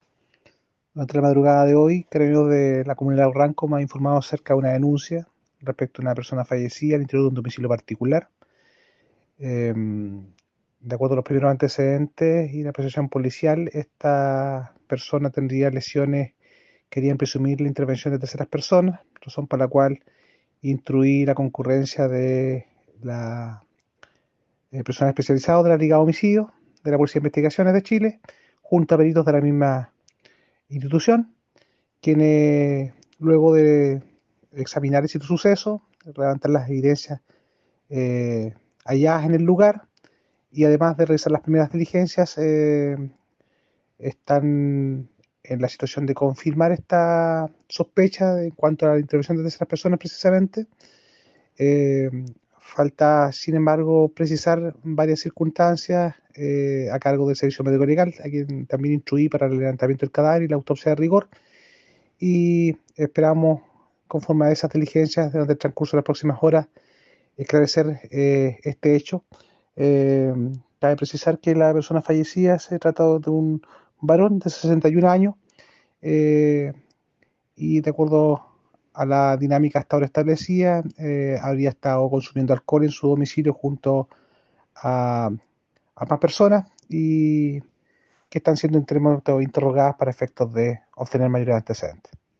Fiscal Sergio Fuentes sobre la investigación que dirige la Fiscalía de Río Bueno por un homicidio ocurrido hoy en la comuna de Lago Ranco.